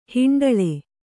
♪ hiṇḍaḷe